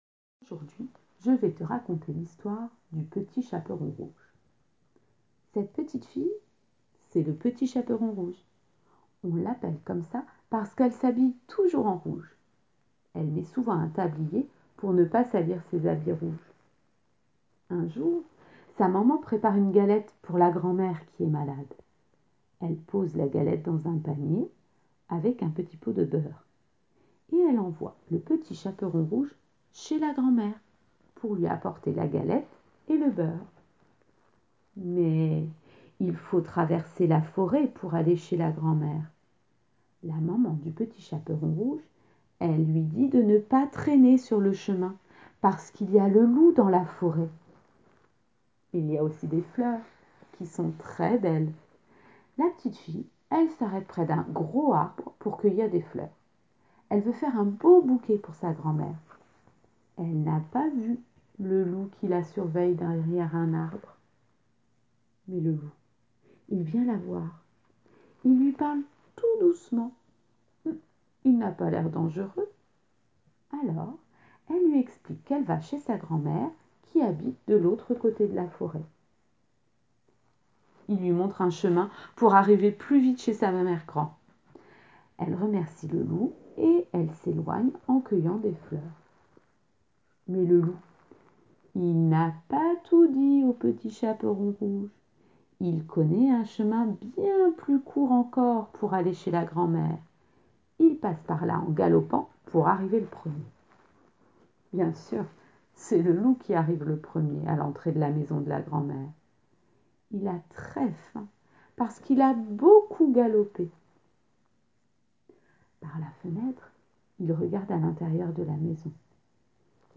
Une nouvelle histoire racontée sans illustrations: Le Petit Chaperon Rouge.